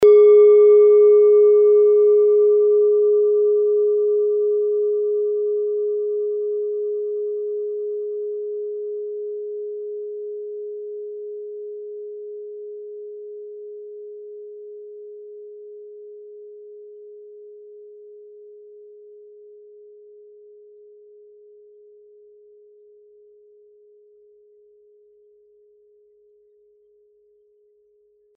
Kleine Klangschale Nr.5
Der Uranuston liegt bei 207,36 Hz und ist die 39. Oktave der Umlauffrequenz des Uranus um die Sonne. Er liegt innerhalb unserer Tonleiter nahe beim "Gis".
kleine-klangschale-5.mp3